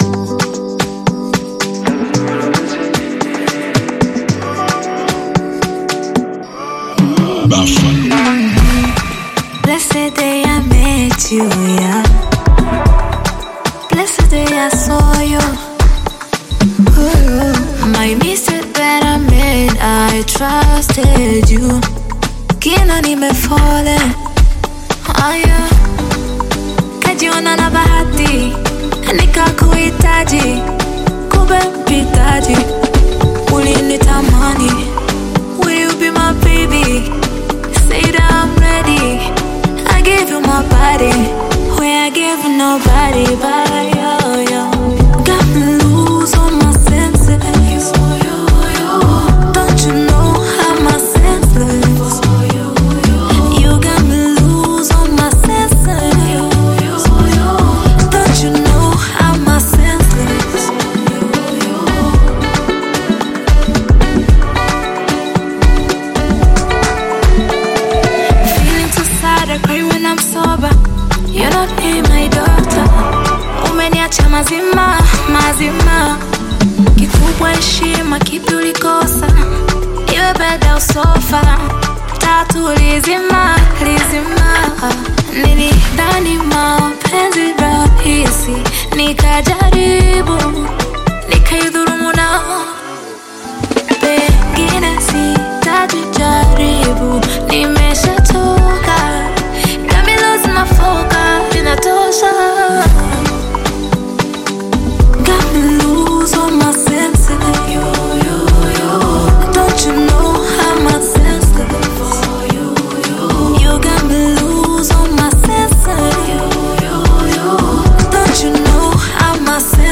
Bongo Flava music track
Bongo Flava